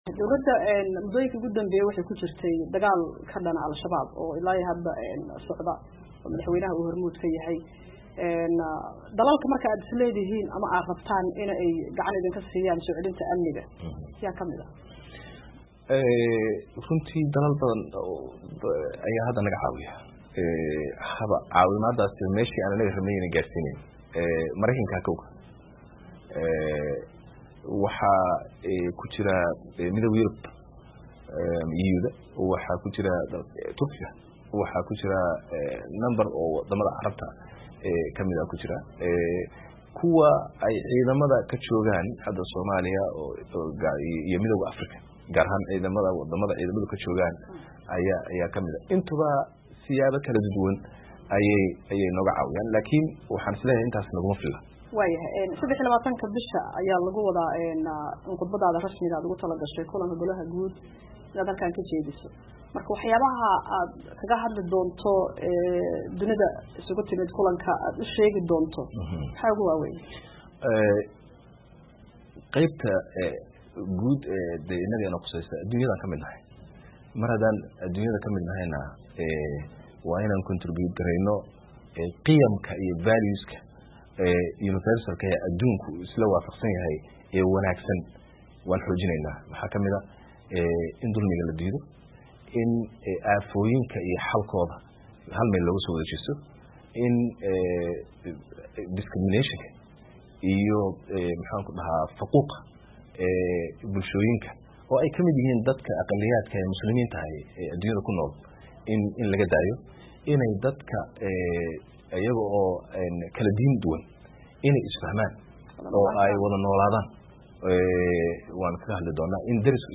Wareysiga Ra'isul Wasaare Xamza Barre (Qeybtii labaad)